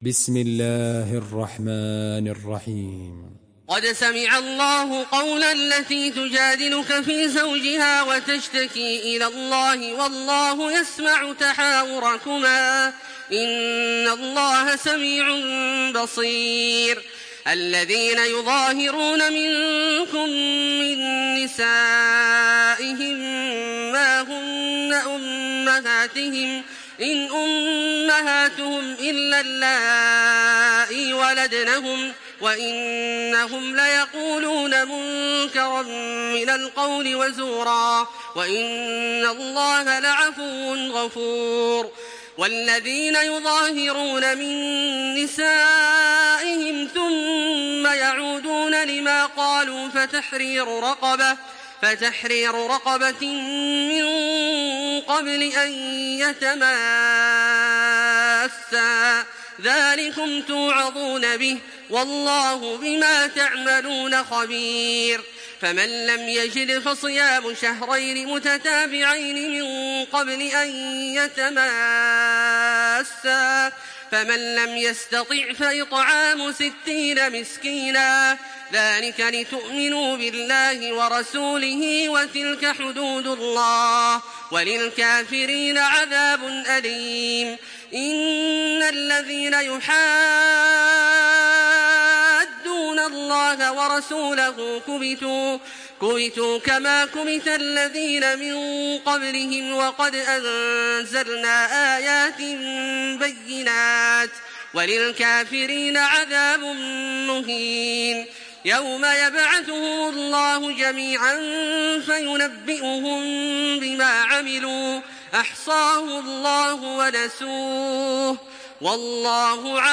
تحميل سورة المجادلة بصوت تراويح الحرم المكي 1426
مرتل